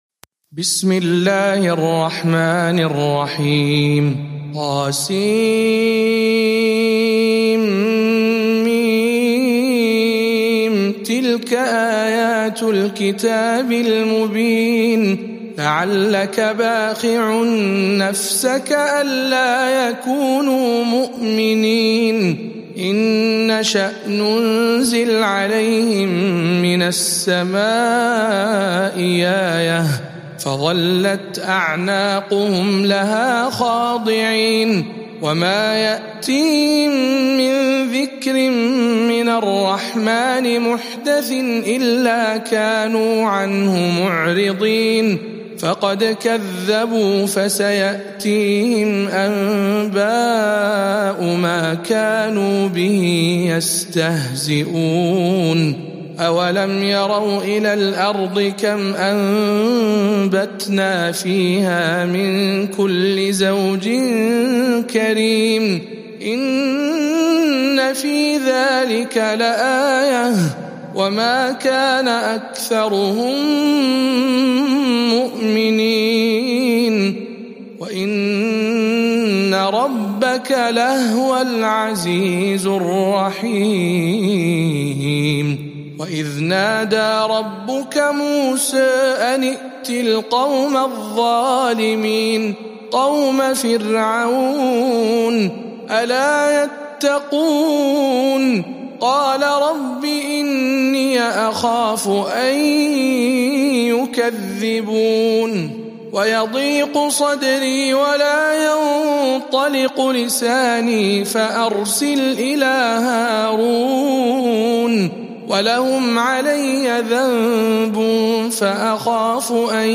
سورة الشعراء برواية الدوري عن أبي عمرو